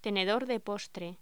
Locución: Tenedor de postre
voz